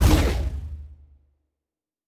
Sci Fi Explosion 14.wav